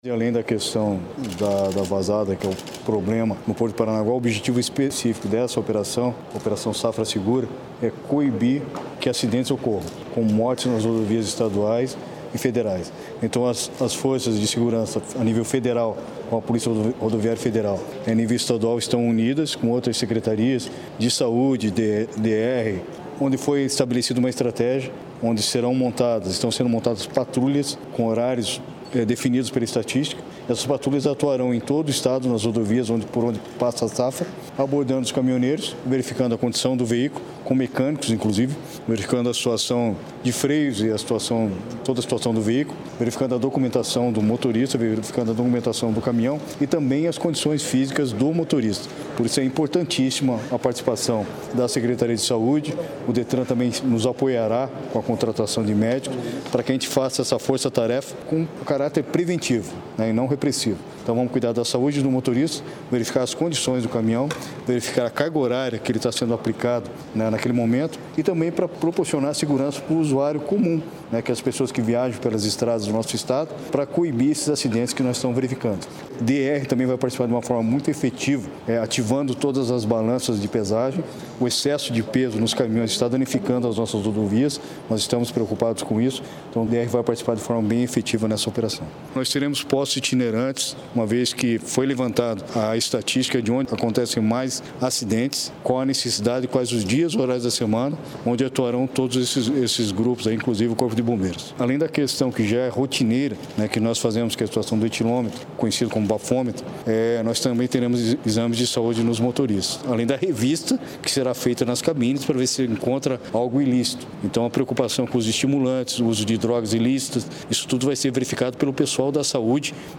Sonora do secretário de Segurança do Paraná, Hudson Teixeira, sobre a Operação Safra nas rodovias estaduais